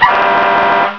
Index of /Sirens
air_short[1].wav